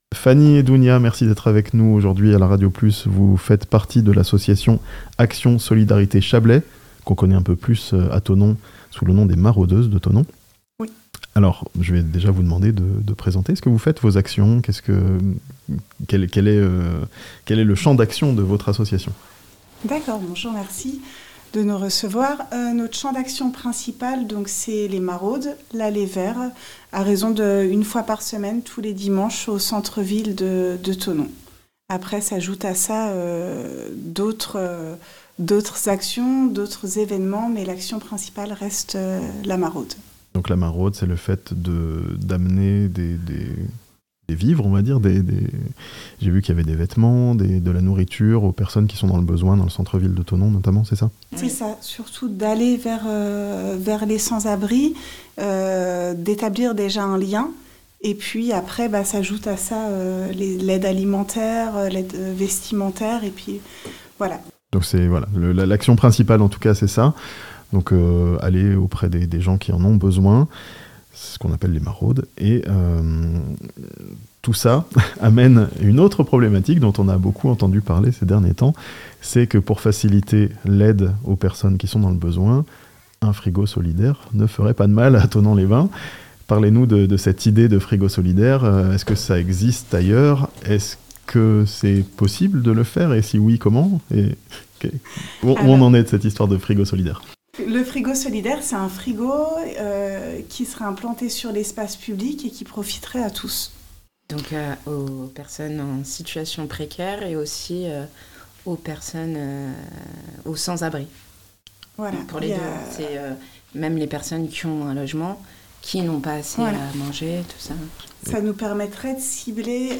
Un frigo solidaire à Thonon? Une association se heurte à un refus d'autorisation (interview)